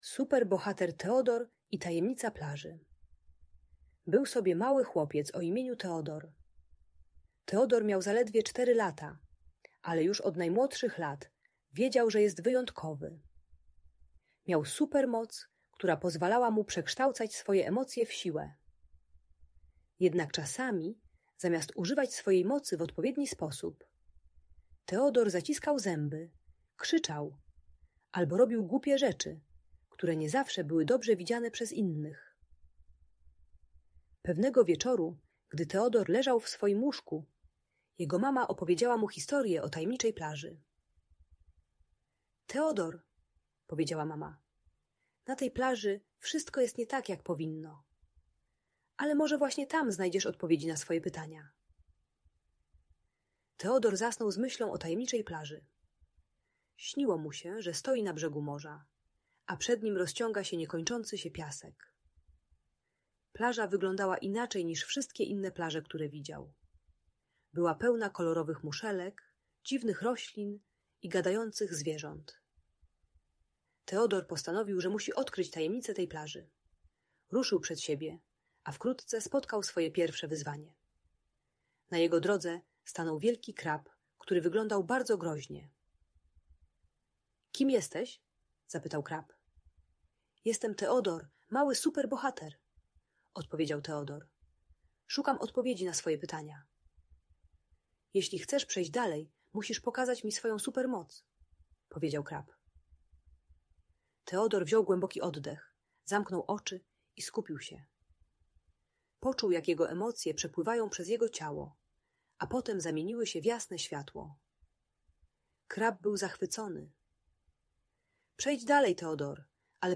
Opowieść o Super Bohaterze Teodorze i Tajemnicy Plaży - Audiobajka dla dzieci